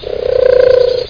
pigeon.mp3